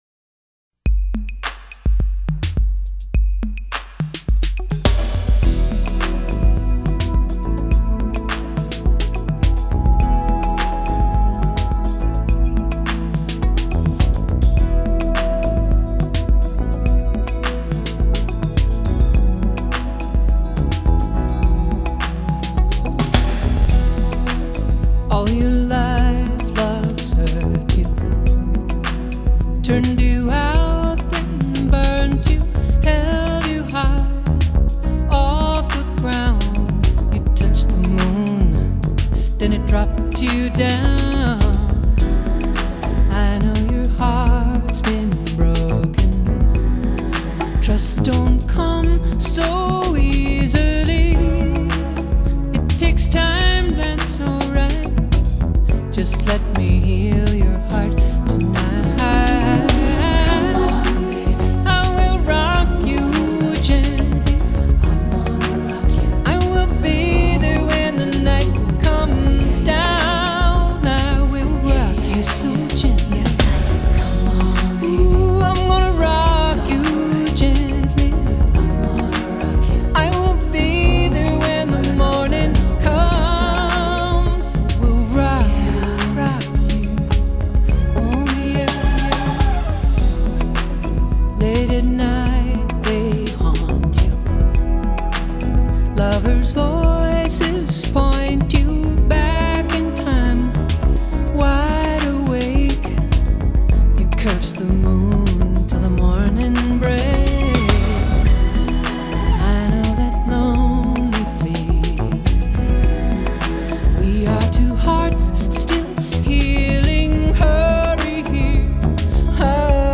歌曲开首就有一个由敲击乐器为主而组成的前奏，给予听众一个开阔的音场感觉。